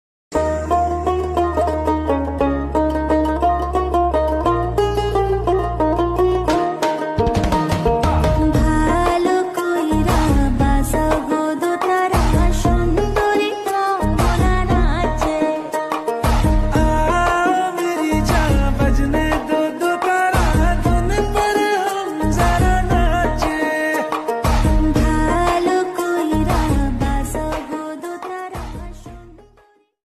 Dotara Ringtone